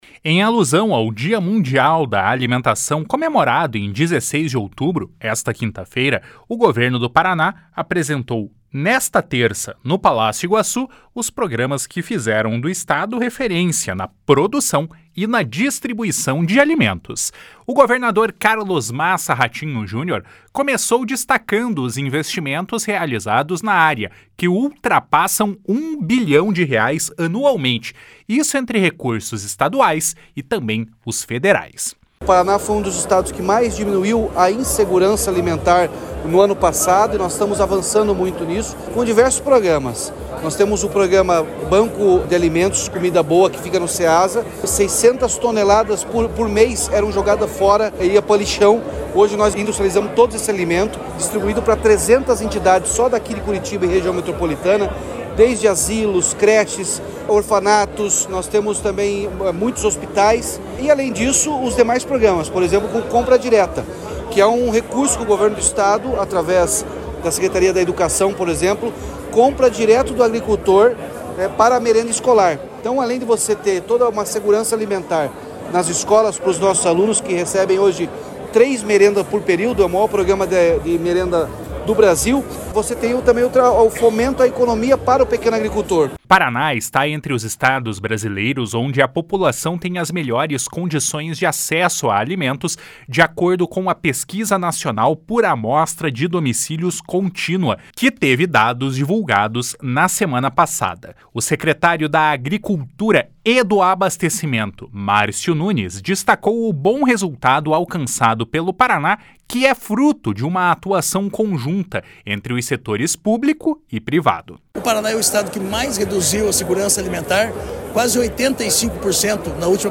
Em alusão ao Dia Mundial da Alimentação, comemorado em 16 de outubro, o Governo do Paraná apresentou nesta terça-feira, no Palácio Iguaçu, os programas que fizeram do Estado referência na produção e distribuição de alimentos.
// SONORA RATINHO JUNIOR //
// SONORA MARCIO NUNES //